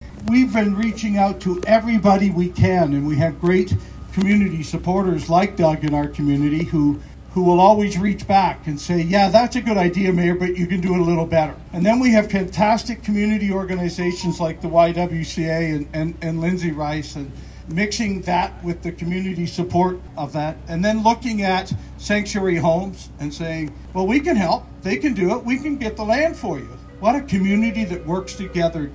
St. Thomas Mayor Joe Preston was on hand for the celebration, who thanked all the partners for their continued collaboration.